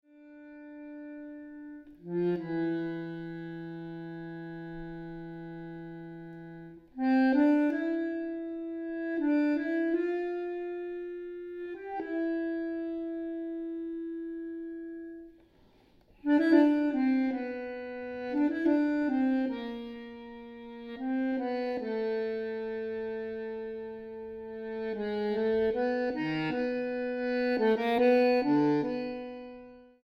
bandoneón